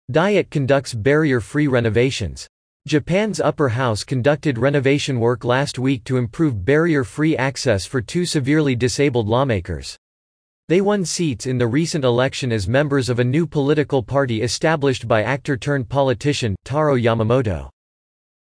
ナチュラルスピードで話される英語は、子音と母音の音がつながったり、子音が脱落して聞こえなくなる現象がよく起こります
※ここでは標準的なアメリカ英語のリスニングを想定しています。